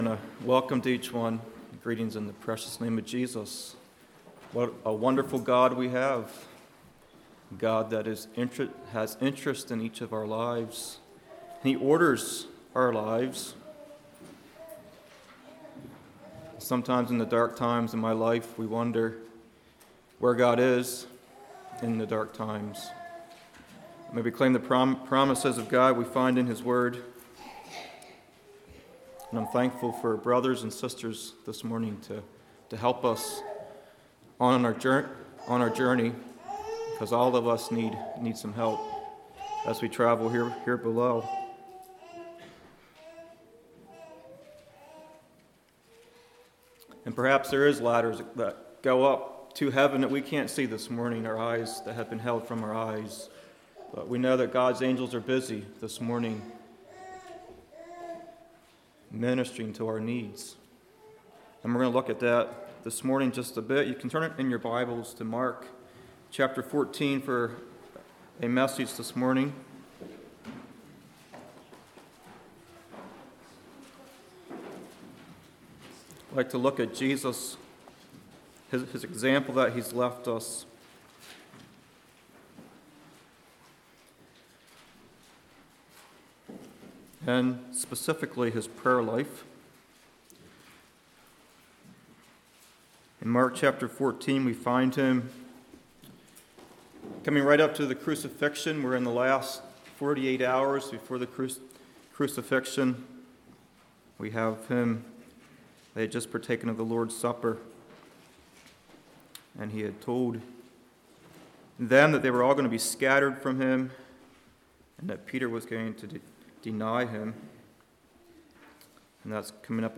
Congregation: Kirkwood